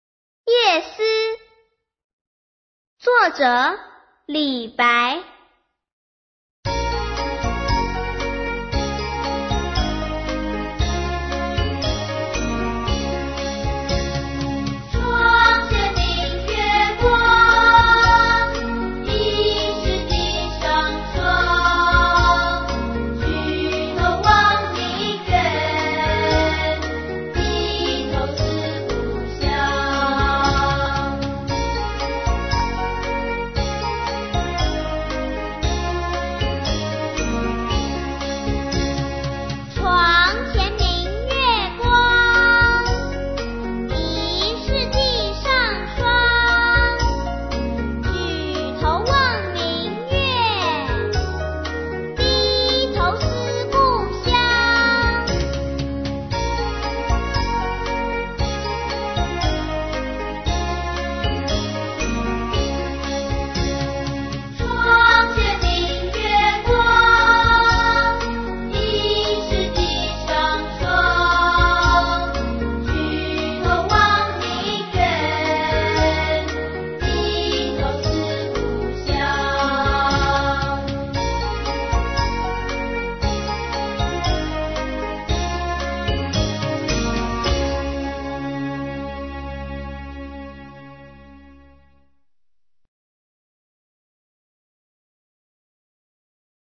● 實體CD附 國語吟唱+台語朗讀 ●